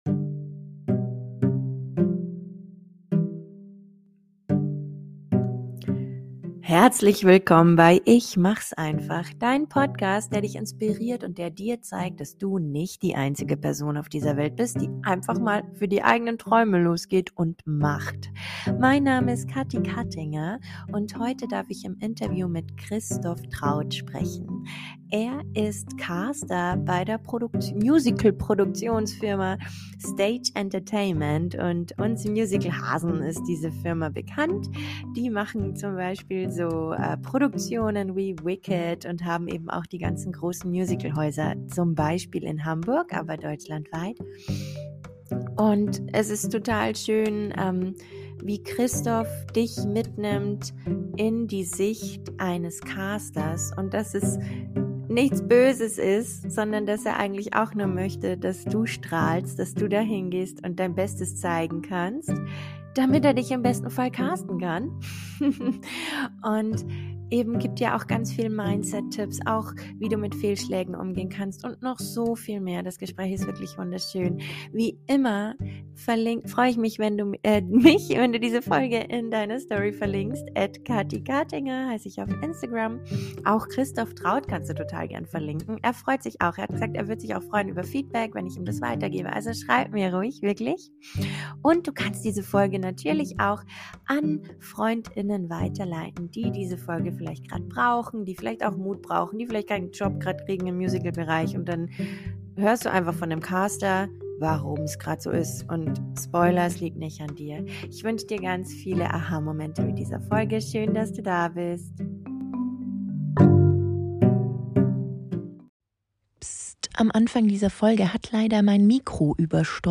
25) Richtiges Mindset für Künstler*innen (Interview